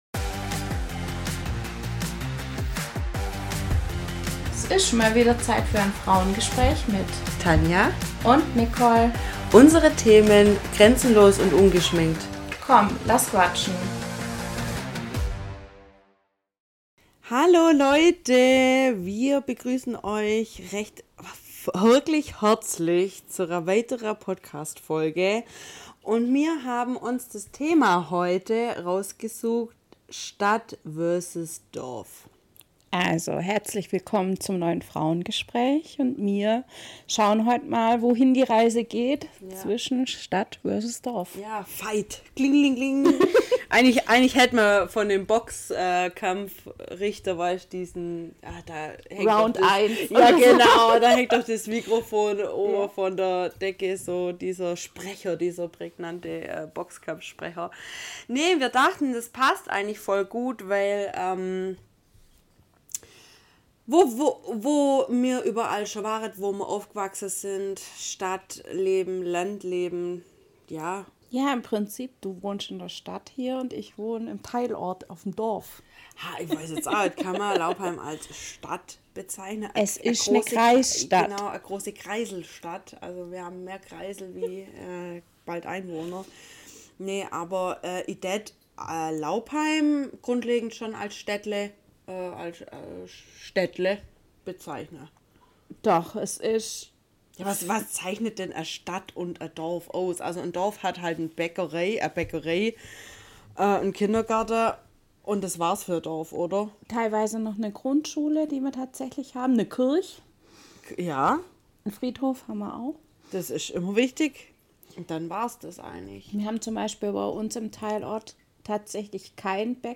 Liebe Leute, Heute haben wir ein grandioses Thema für euch im Gepäck… Zur 24ten Folge haben wir für unser Frauengespräch das Thema „Stadtleben vs. Dorfleben“ uns überlegt.